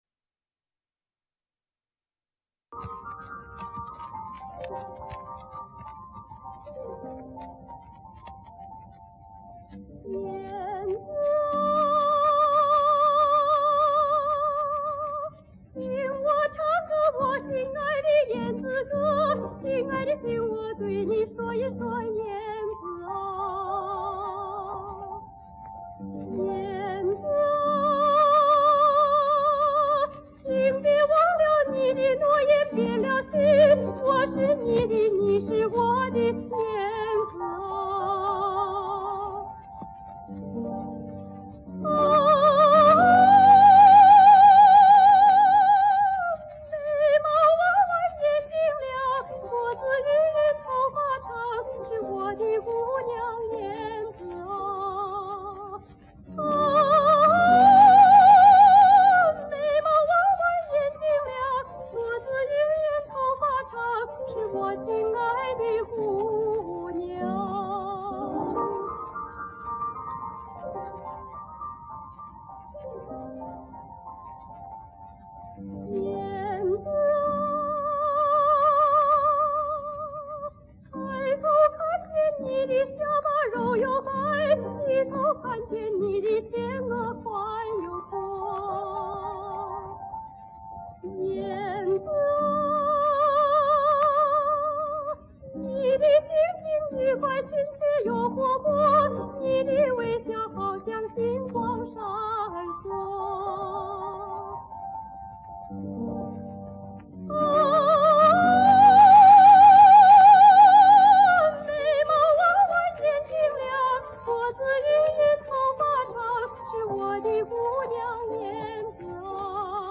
女高音歌唱家
哈萨克民歌
钢琴伴奏